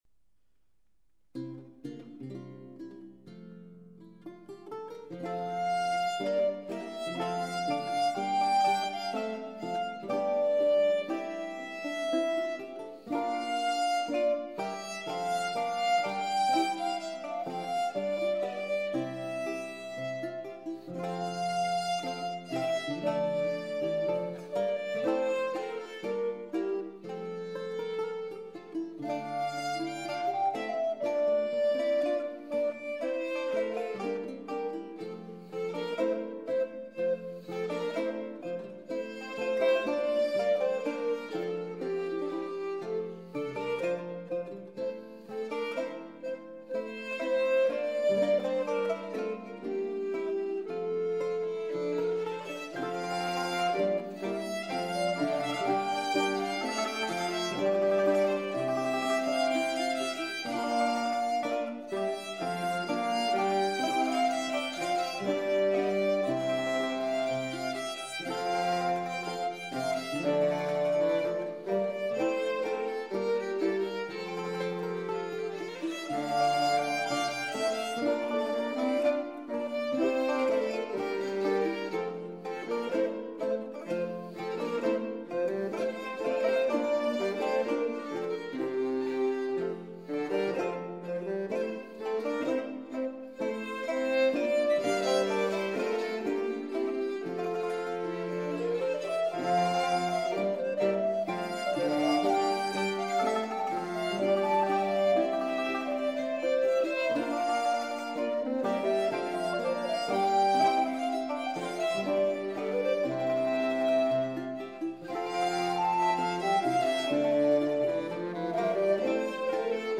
pavane : une danse lente, qui se pratique en couple, la femme à la droite de l'homme.
pavane.mp3